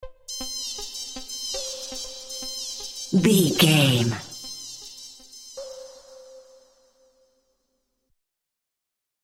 Modern Pop Top 40 Electronic Dance Music Sting.
Fast paced
Aeolian/Minor
Fast
dark
futuristic
epic
groovy
aggressive
repetitive
synthesiser
drums
drum machine
house
techno
trance
instrumentals
synth leads
synth bass
upbeat